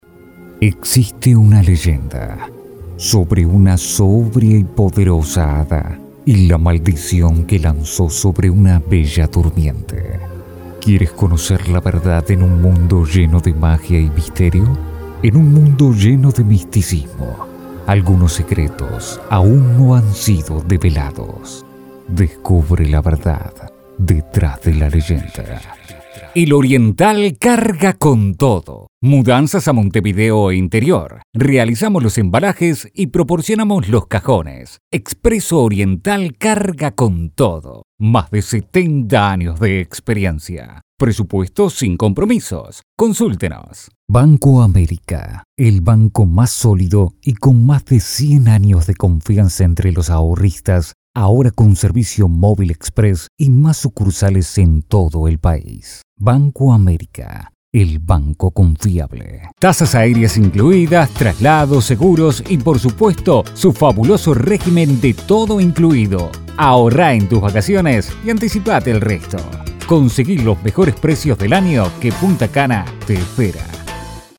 Masculino
Espanhol - Argentina
Voz Padrão - Grave 01:14